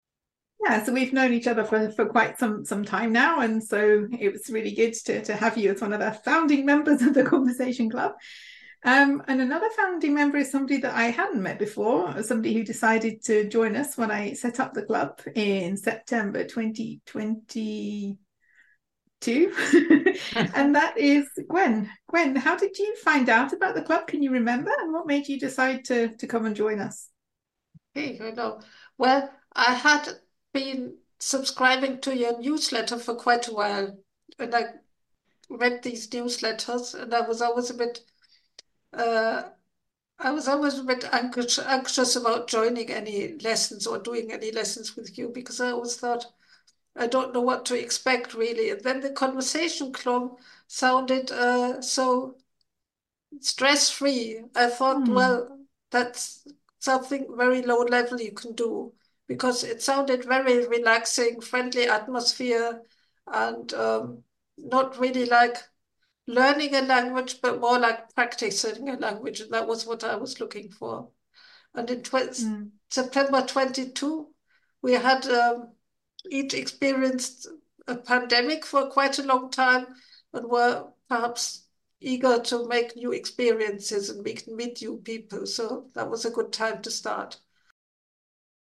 Some of the regular members spoke with me on the podcast.